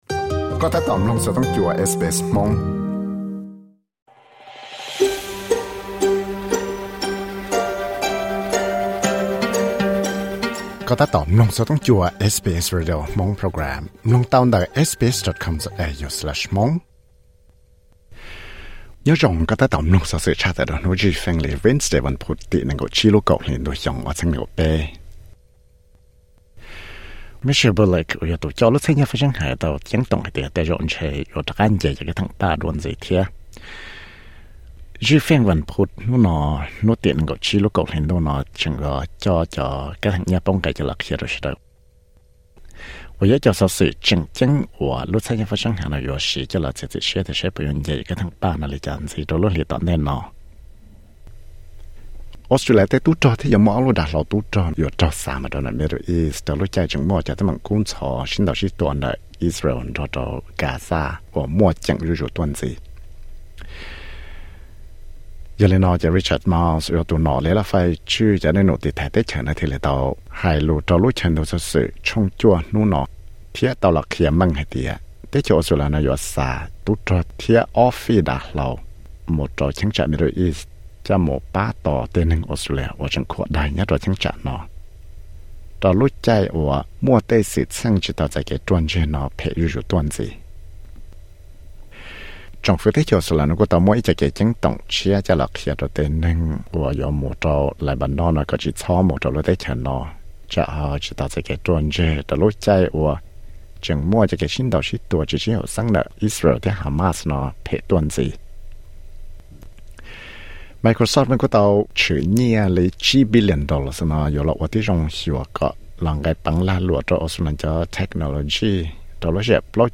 Xov xwm tshaj tawm hnub zwj Feej (Wenesday newsflash 25.10.2023)